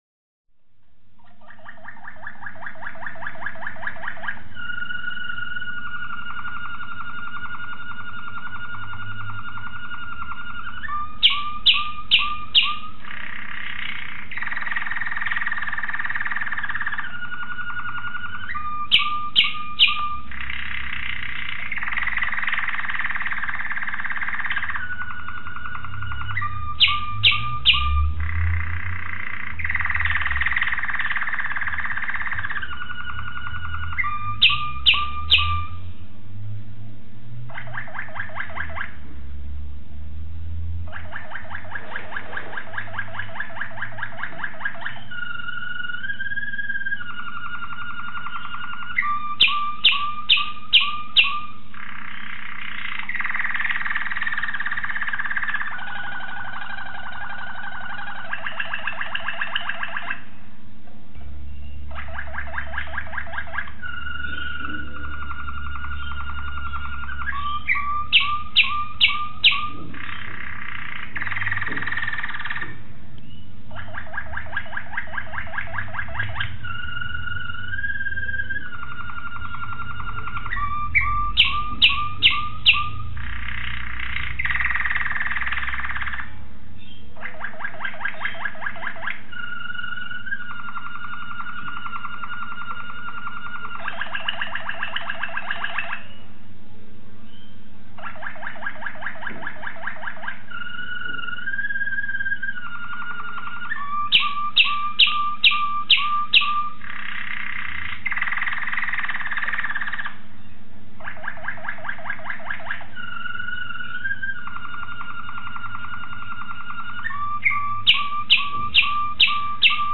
Suara Burung Kenari Waterslager
1. Suara Kenari Waterslanger Gacor Wajib Punya
1.-Suara-Kenari-Waterslanger-Gacor-Wajib-Punya.mp3